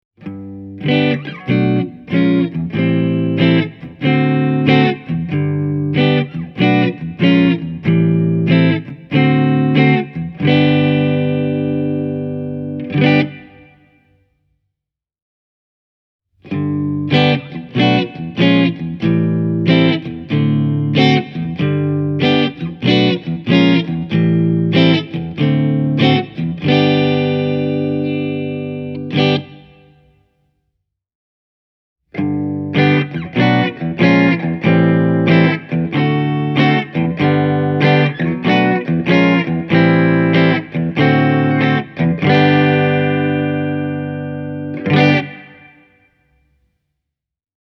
Modern Cleanissä kombo toimii AB-luokan periaatteella, jolloin bassorekisteri on muhkeampi, ja signaali pysyy siistinä miltein koko Volume-säätimen skaalalla.
Tällaisia soundeja irtosi Blackstar HT Club 40 -kombosta, kun soitin sen läpi Hamer USA Studio Custom ja Gibson Les Paul Junior kitarani:
Hamer Studio Custom – Modern Clean
hamer-studio-custom-e28093-modern-clean.mp3